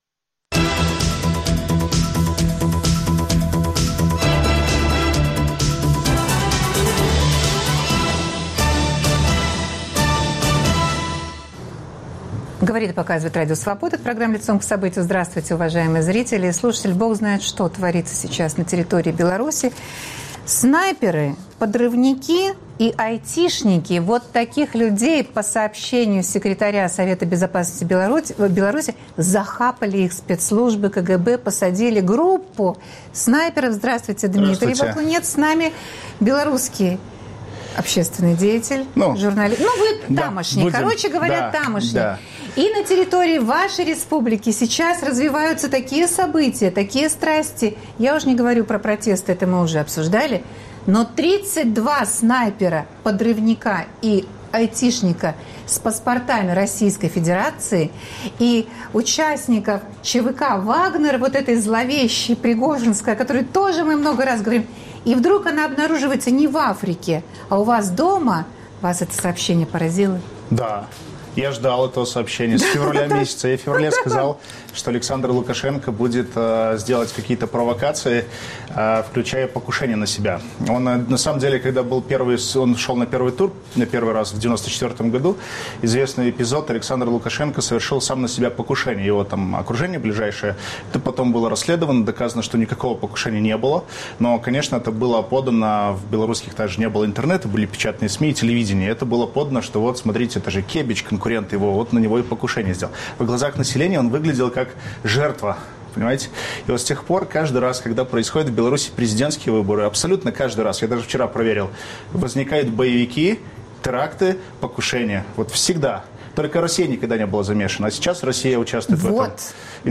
Разбираемся с экспертами.